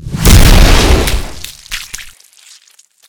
Disintegrate.ogg